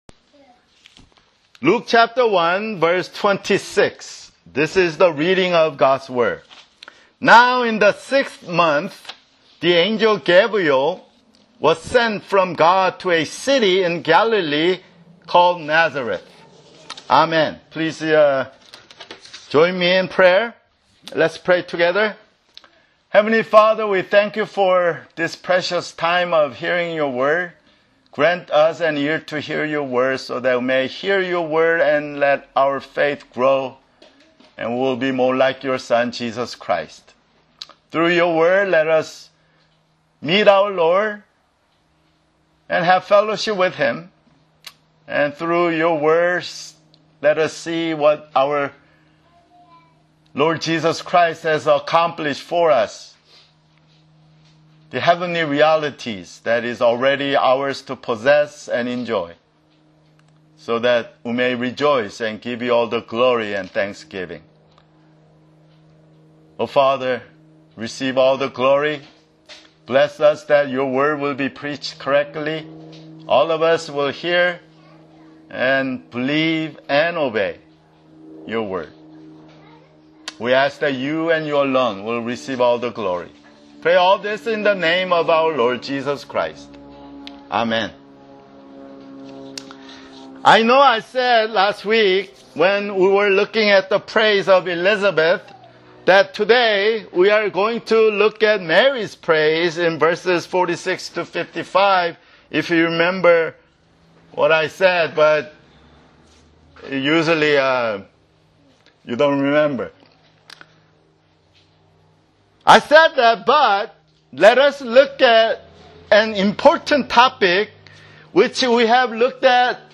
[Sermon] Luke (9)